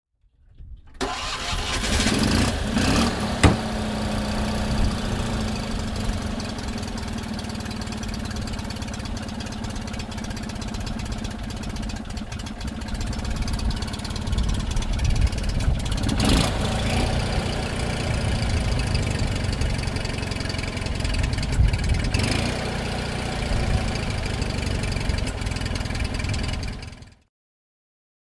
Motorsounds und Tonaufnahmen zu Abarth Fahrzeugen (zufällige Auswahl)
Fiat-Abarth 595 SS (1969) - Starten und Leerlauf